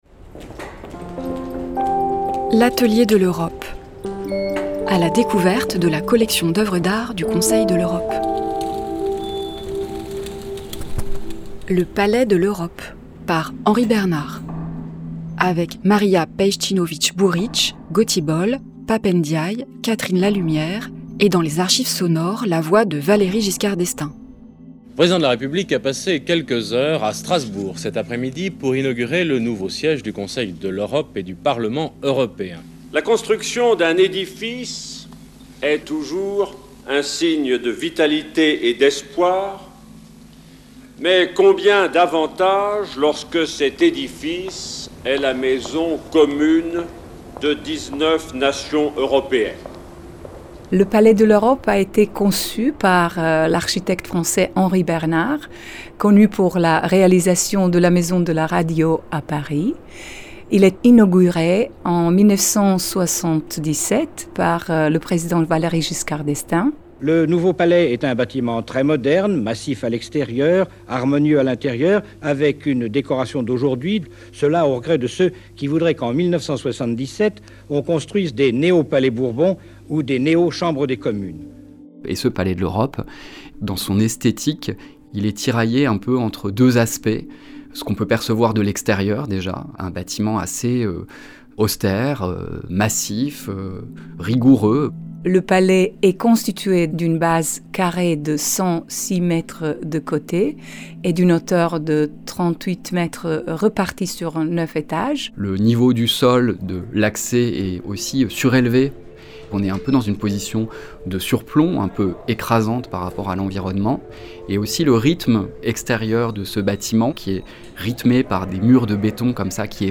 Marija Pejčinović Burić, Secrétaire Générale du Conseil de l’Europe (2019-2024)
Pap Ndiaye, Ambassadeur, Représentant permanent de la France auprès du Conseil de l’Europe
Catherine Lalumière, ancienne Secrétaire Générale du Conseil de l’Europe (1989-1994)
Archive sonore : Valéry Giscard d’Estaing